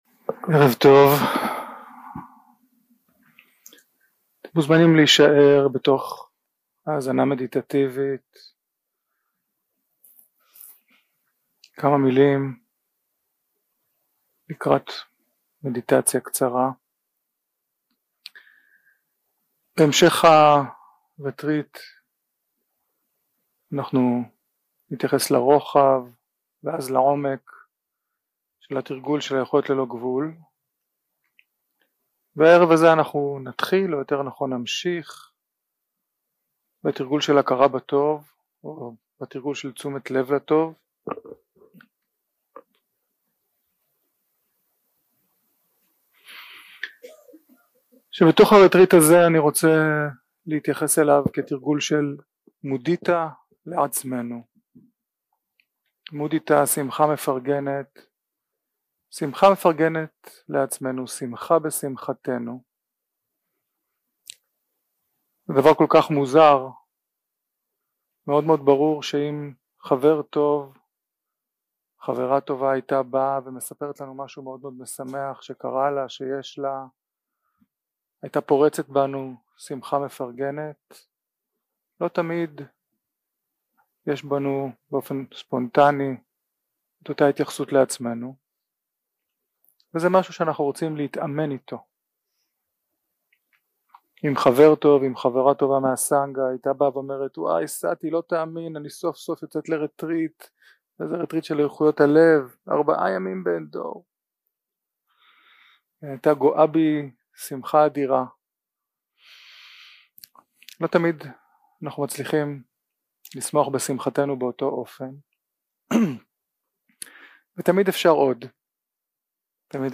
יום 1 – הקלטה 1 – ערב – מדיטציה מונחית – תרגול הכרה בטוב | Tovana
Guided meditation שפת ההקלטה